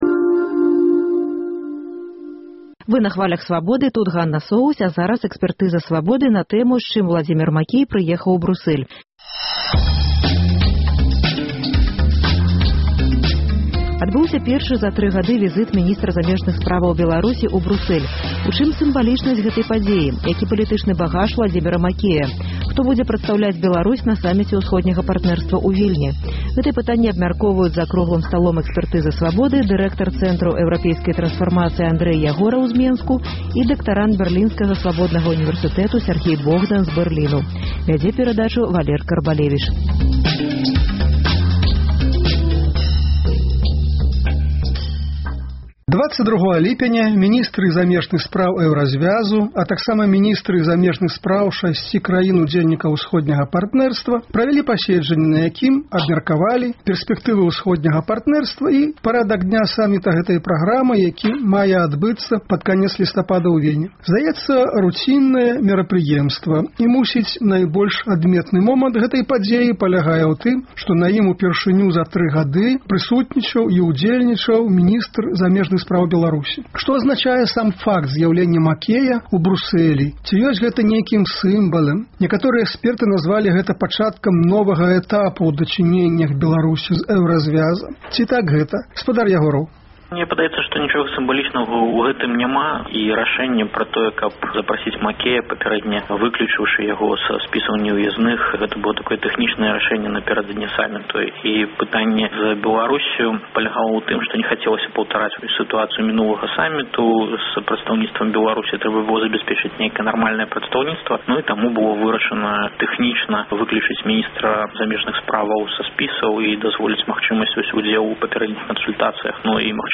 Хто будзе прадстаўляць Беларусь на саміце "Ўсходняга партнэрства" ў Вільні? Гэтыя пытаньні абмяркоўваюць за круглым сталом